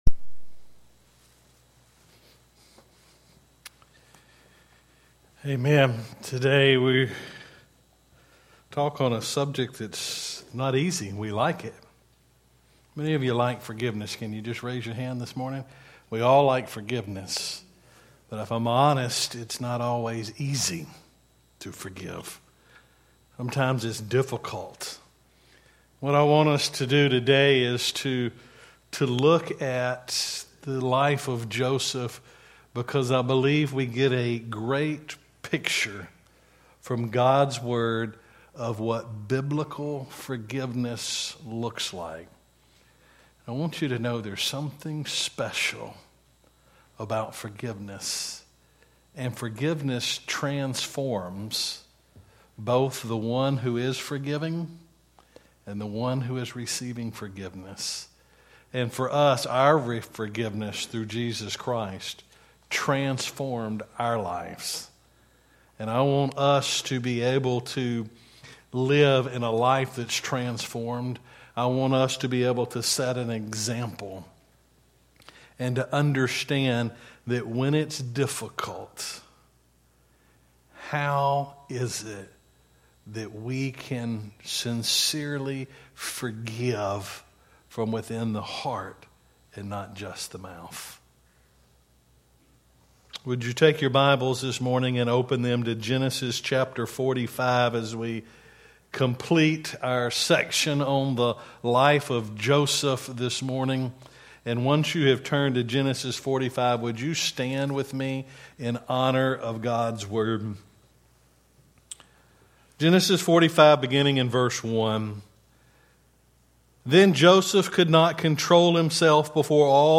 Sermons by Mt. Olivet Baptist Church Stanley, LA